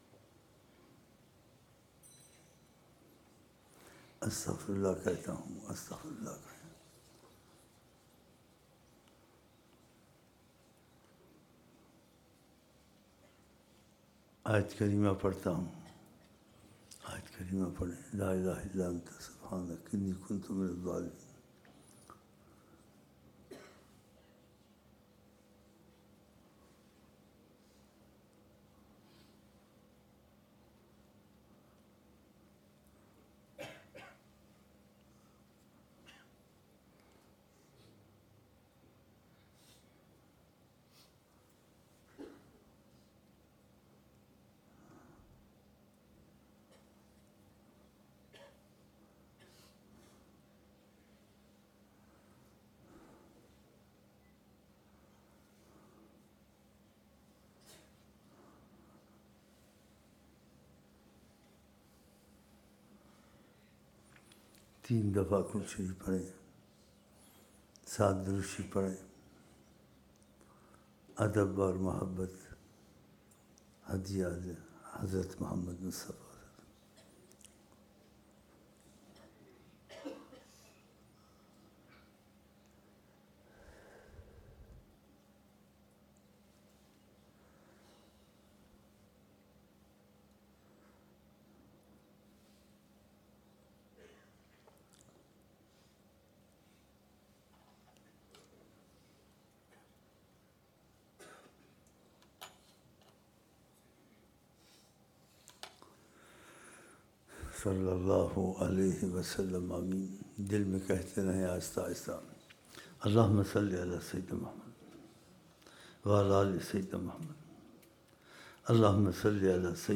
Dua 01 January 2007 Eid Ul Azha